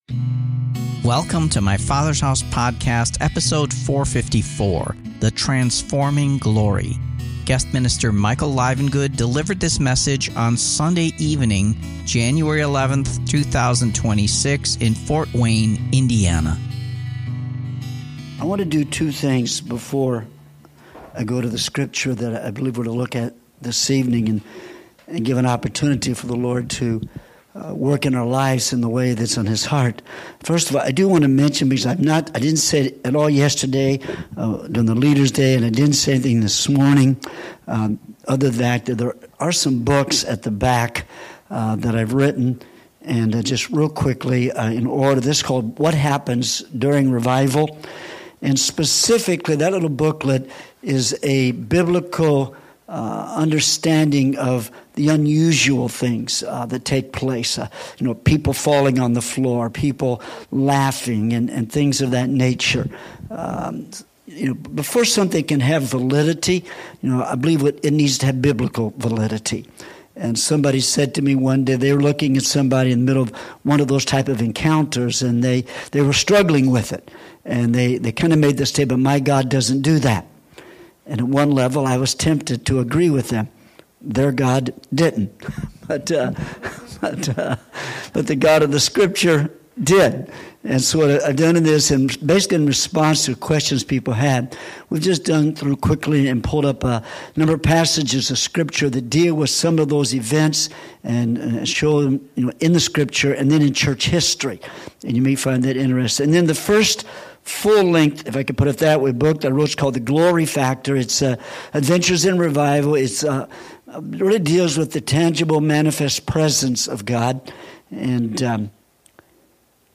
Guest minister